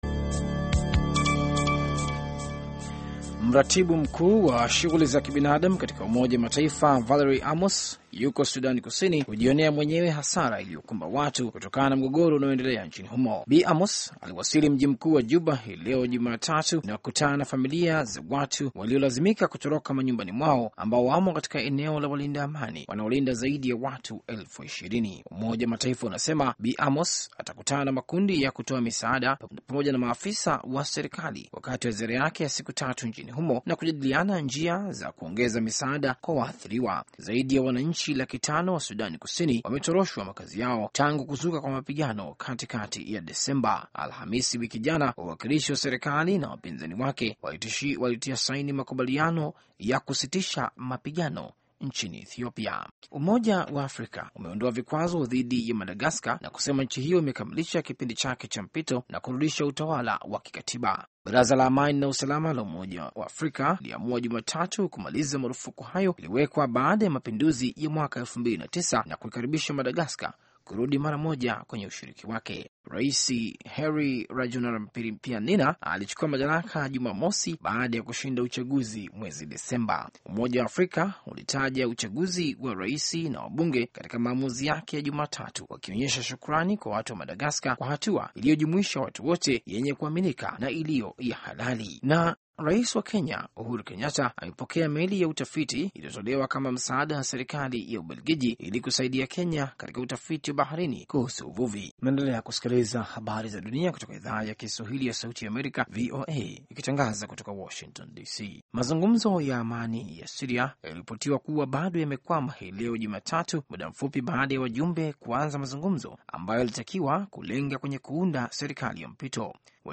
Taarifa ya Habari VOA Swahili - 6:08